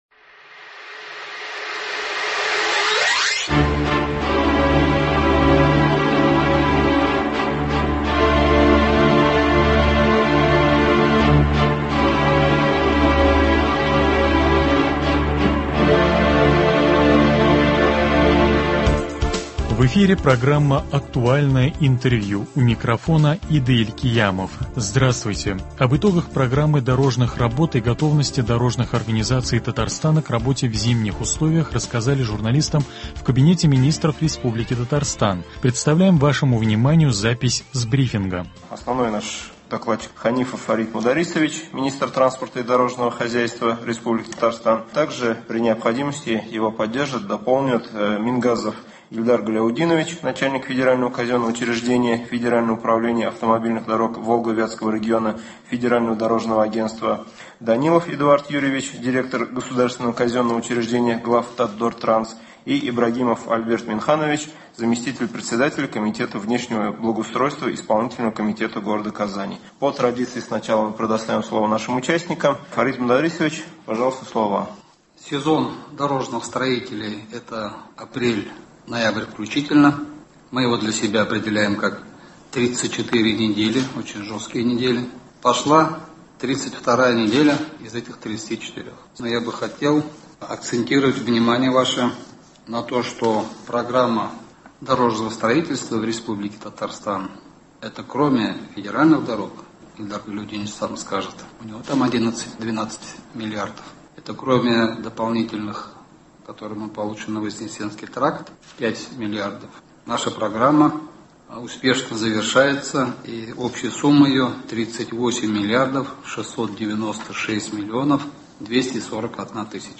Актуальное интервью (24.11.21)